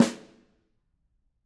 Snare2-HitSN_v7_rr2_Sum.wav